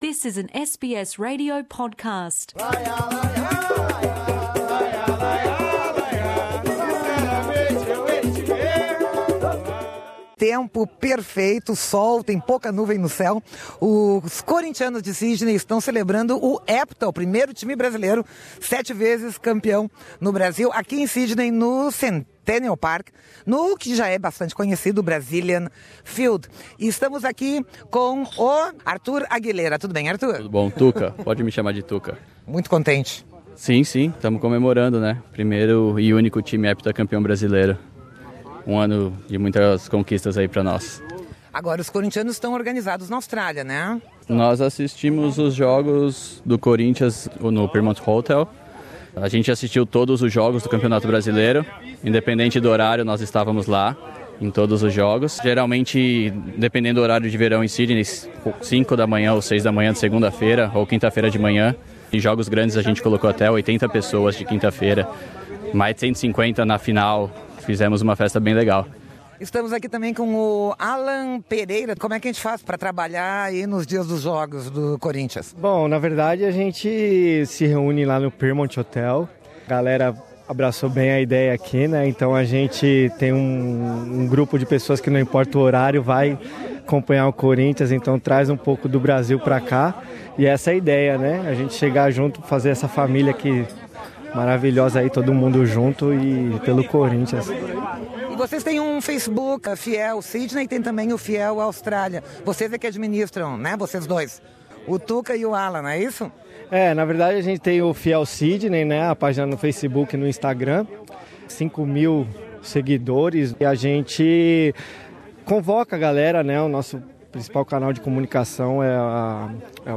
Podcast com os Corinthianos de Sydney celebrando o Hepta Campeão com churrasco e muito samba.